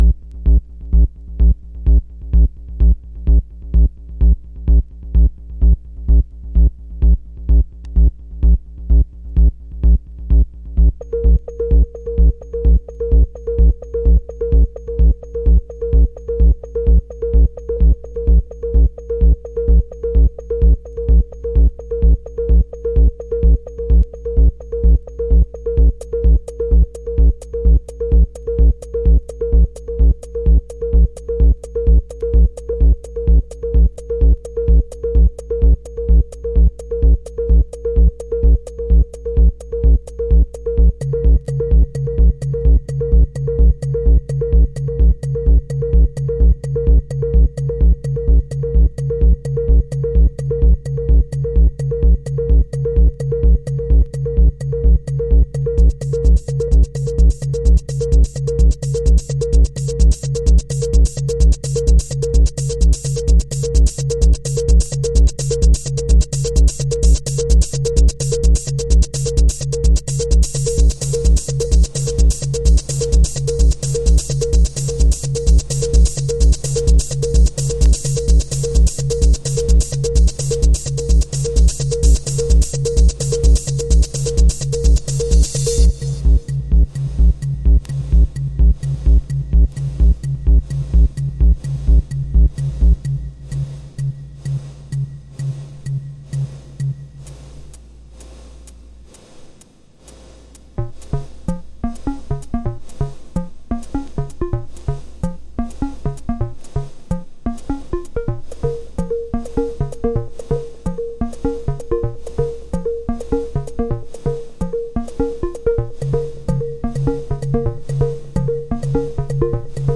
デトロイトテクノへの愛情と憧憬が詰まった3トラック